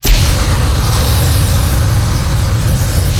flamer_fire_lp.wav